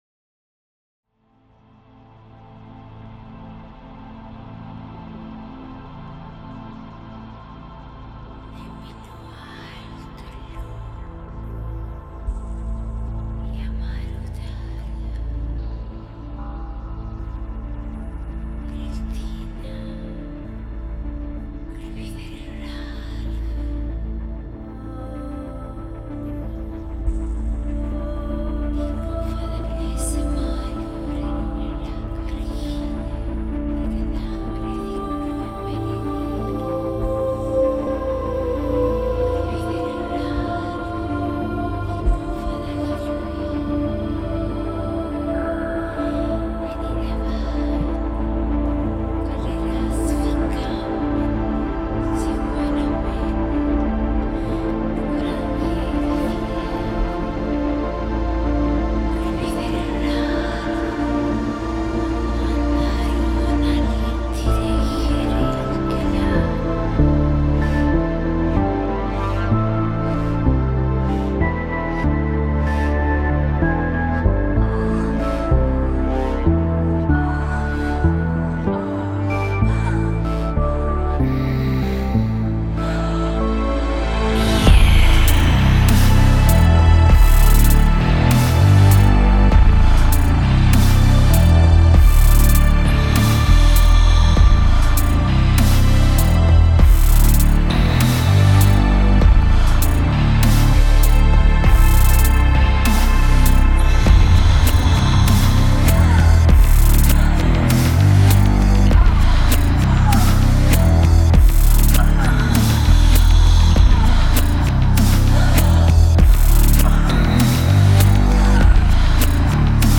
Нашлось больше кликов, чем Вами обозначено, убрал почти всё.